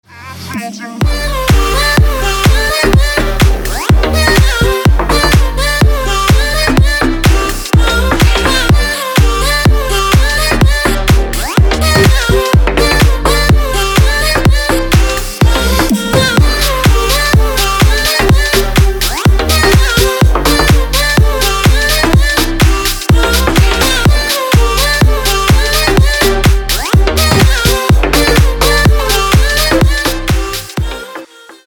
• Качество: 256, Stereo
EDM
club
electro